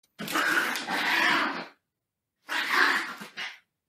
На этой странице собраны звуки драки между кошкой и собакой – от яростного шипения и лая до забавного визга и рычания.
Домашняя кошка вступает в драку с маленькой собакой